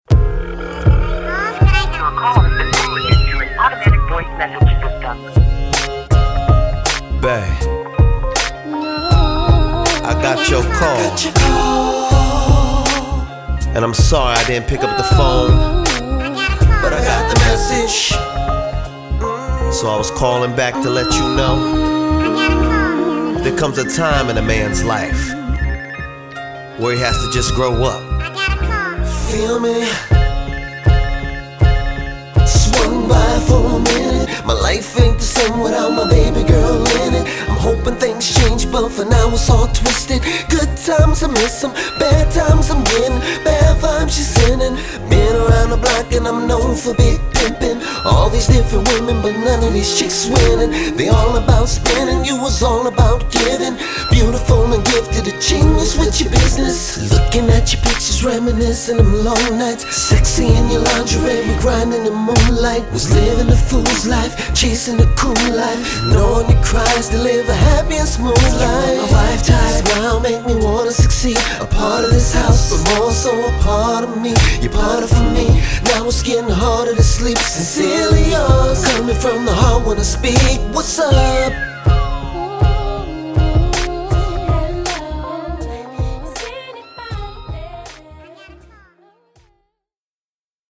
Genre: R&B/Hip–Hop/Soul